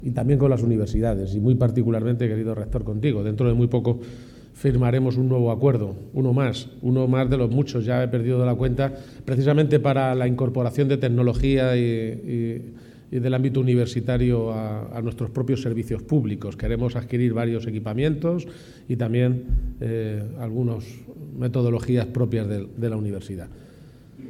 Presidente Viernes, 16 Mayo 2025 - 12:15pm El presidente de Castilla-La Mancha, Emiliano García-Page, ha anunciado durante la Jornada de Tecnodependencia que se está celebrando en Alcázar de San Juan, que el Gobierno de Castilla-La Mancha firmará próximamente un convenio con la UCLM para adquirir equipos y mejorar el conocimiento en el ámbito de la robótica y la inteligencia artificial al servicio de los cuidados. garcia_page-_convenio_uclm.mp3 Descargar: Descargar